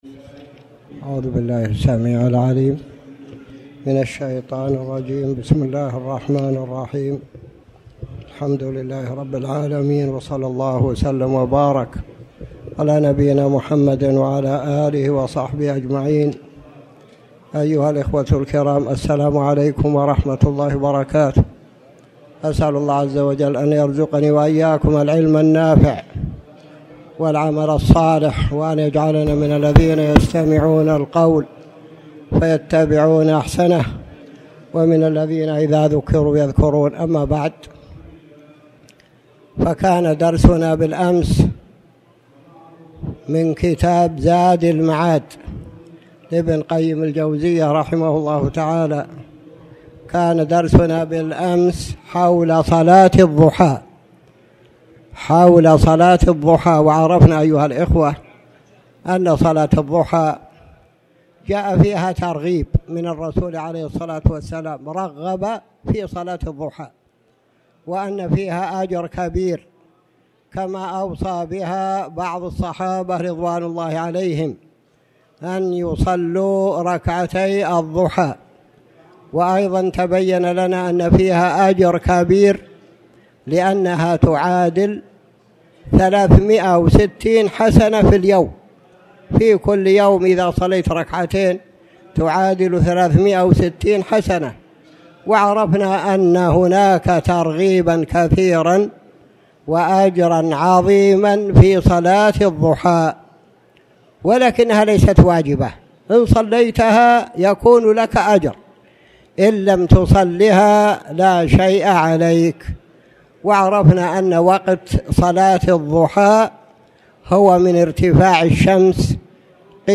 تاريخ النشر ١٦ ذو الحجة ١٤٣٩ هـ المكان: المسجد الحرام الشيخ